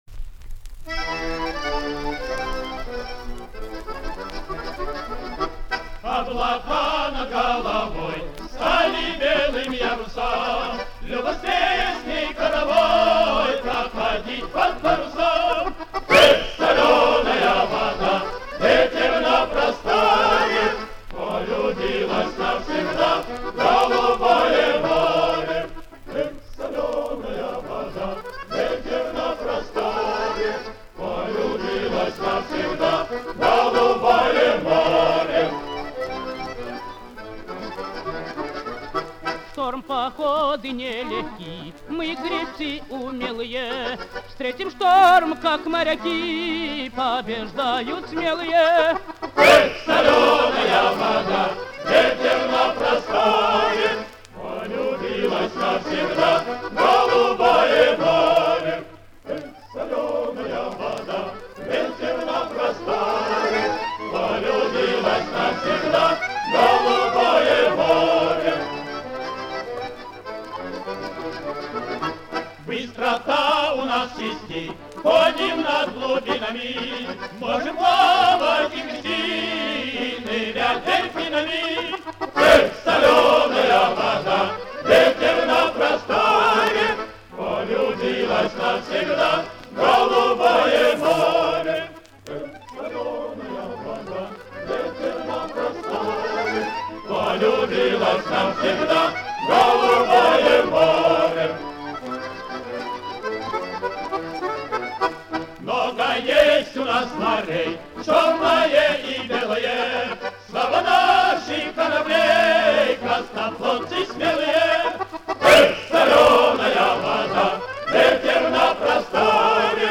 в сопр. баянов